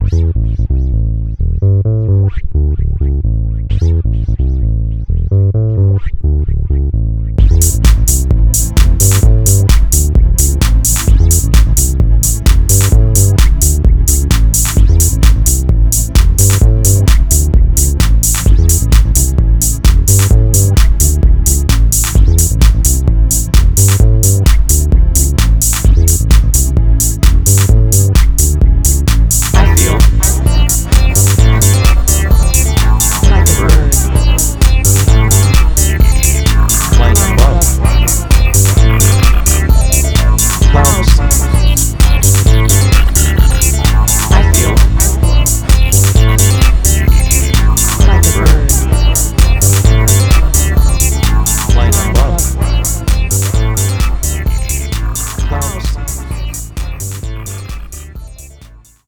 Genre Dance/Techno